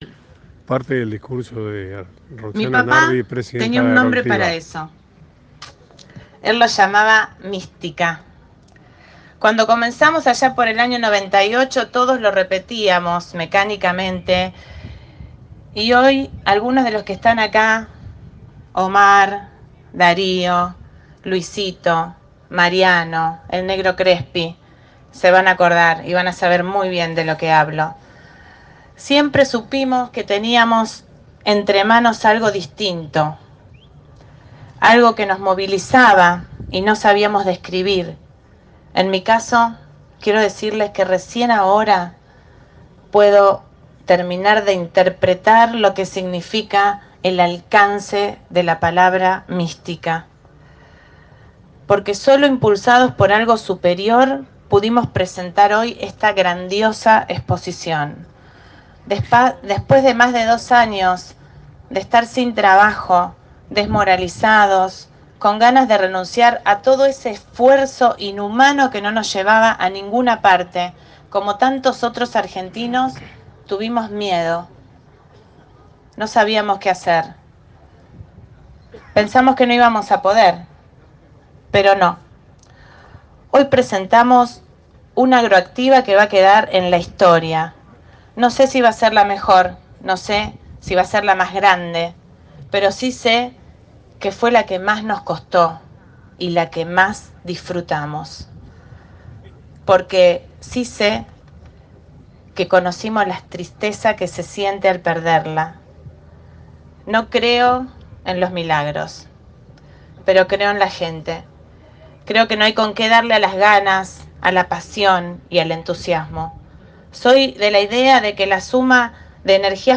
La inauguración de la 28 edición de AgroActiva convocó emociones, recuerdos, abrazos, encuentros y reencuentros frente al pórtico de entrada de la megamuestra.